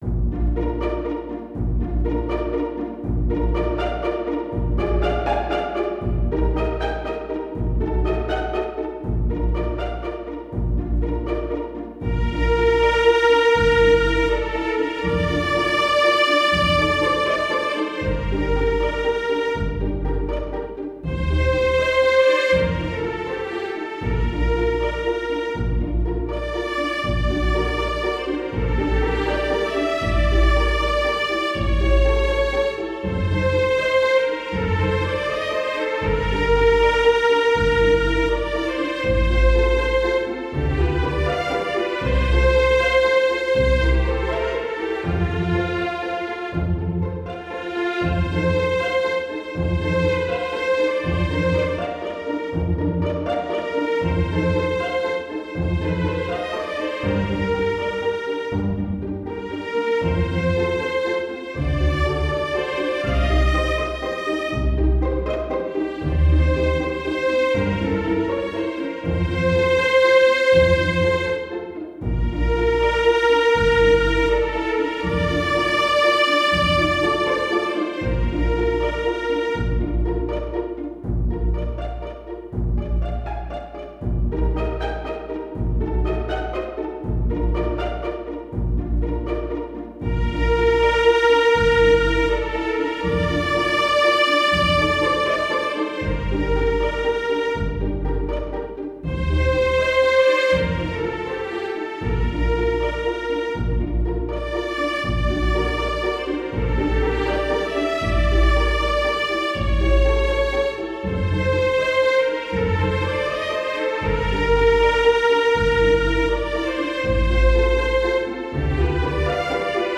para Orquestra de Cordas
● Violino I
● Violino II
● Viola
● Violoncelo
● Contrabaixo